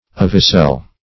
Search Result for " ovicell" : The Collaborative International Dictionary of English v.0.48: Ovicell \O"vi*cell`\, n. [Ovum + cell.]